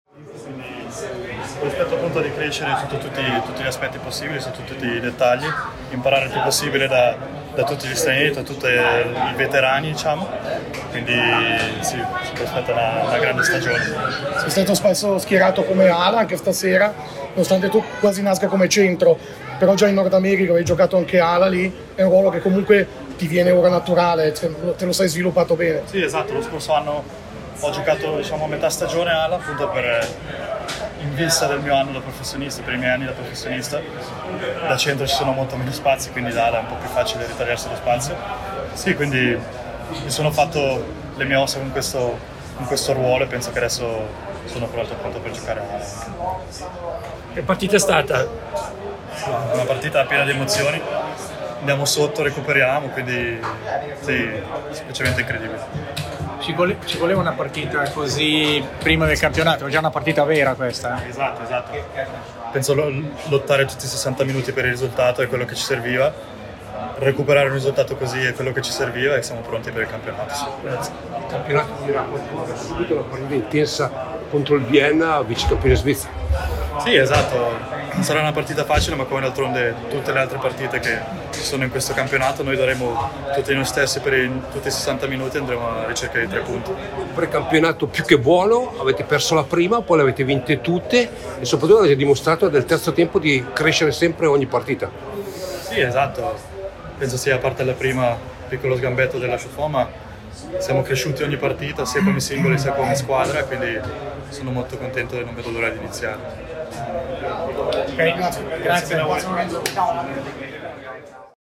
Interviste: